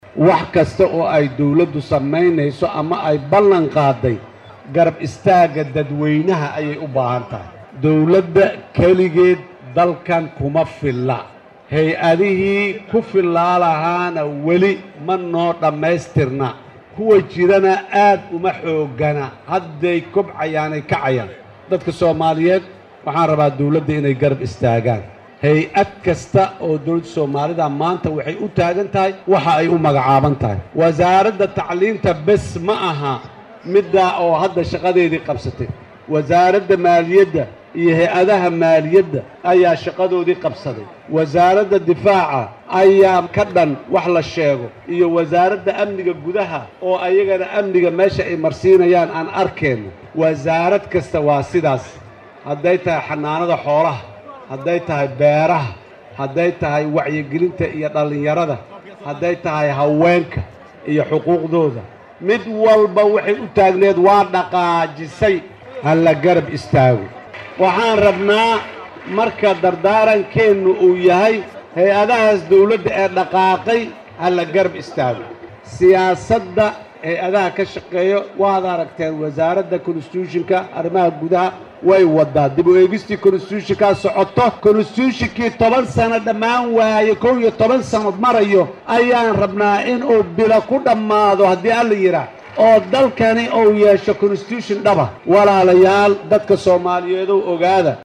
Madaxweynaha dalka Soomaaliya Xasan Sheekh Maxamuud oo khudbad ka jeedinayay munaasabada 1-da Luulyo ayaa ka hadlay saldhigyada ciidmada dowladda ay kala wareegeen howlgalka midowga Afrika ee ATMIS.